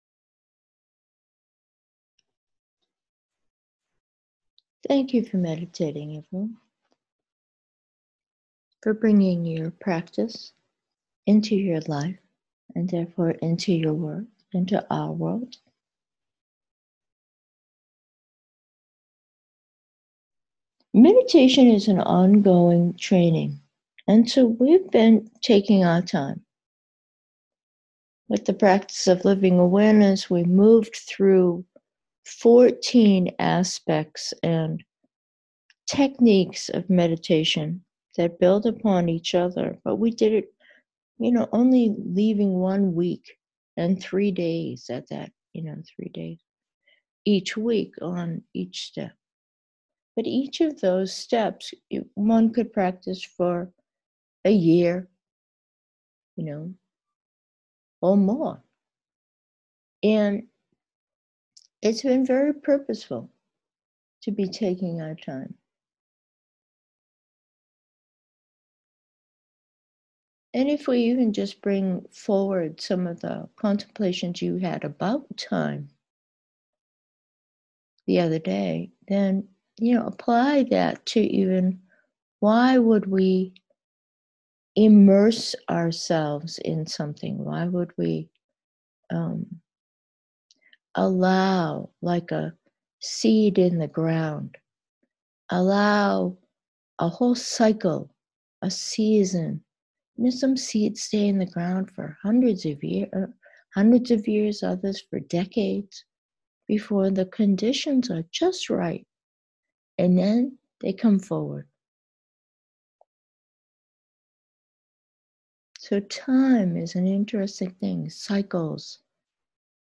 Meditation: have confidence